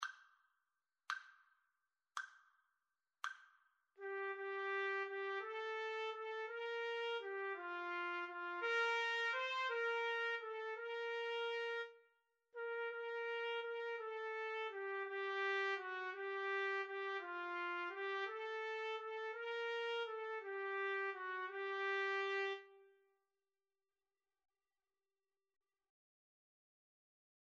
One in a bar . = 56
3/4 (View more 3/4 Music)
G minor (Sounding Pitch) A minor (Trumpet in Bb) (View more G minor Music for Trumpet Duet )
Trumpet Duet  (View more Easy Trumpet Duet Music)
Traditional (View more Traditional Trumpet Duet Music)